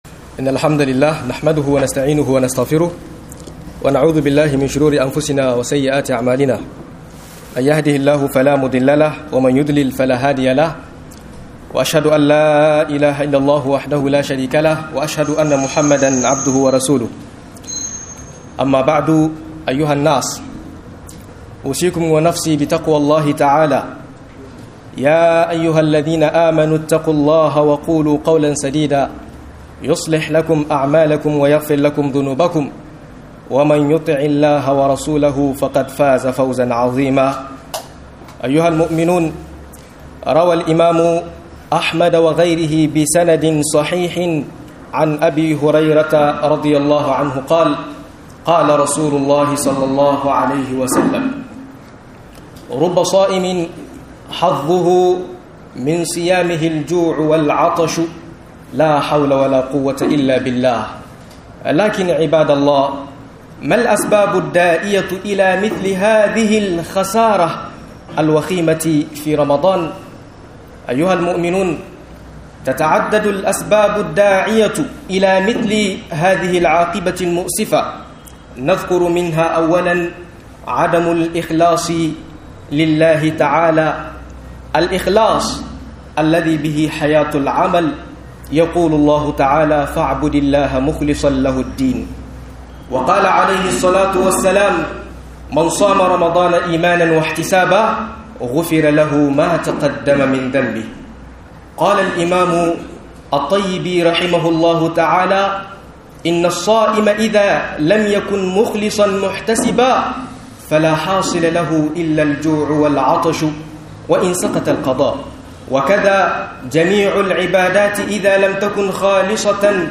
Assara may Girma Acikin Ramadan - MUHADARA